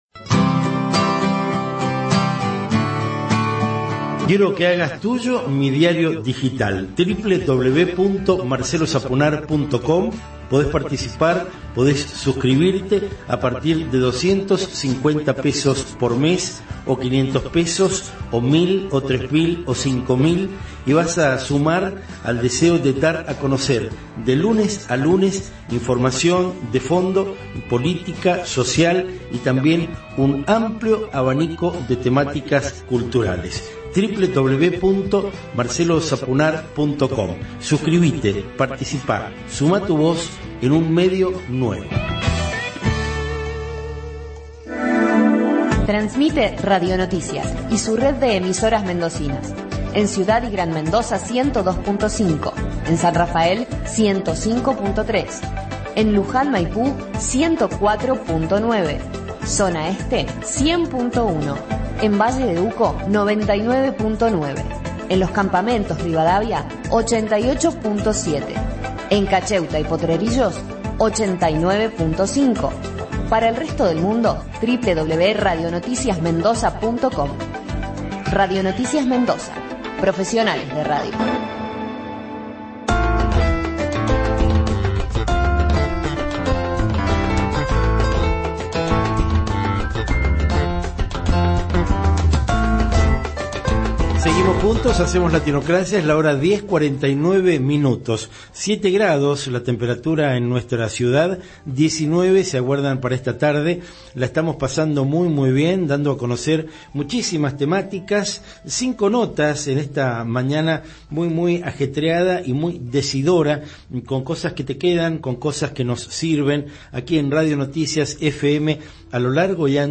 Entrevista: Marisa Garnica, Diputada Provincial, Latinocracia, 6 de junio de 2022, por Radio Noticias FM 102,5